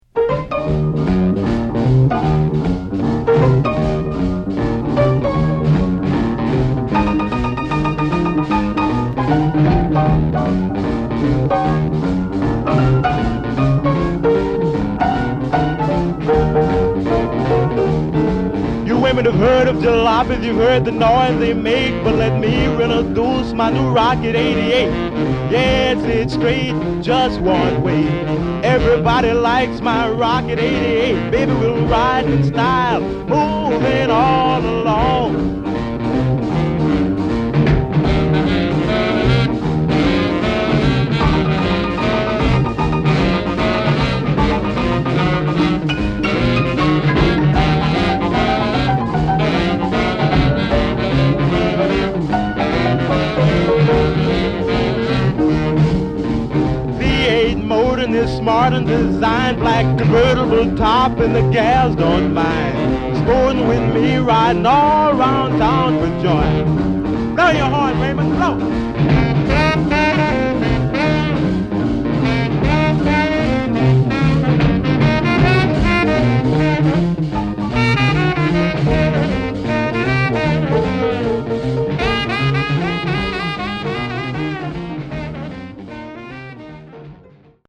Genre: Rockin' RnB
lead guitar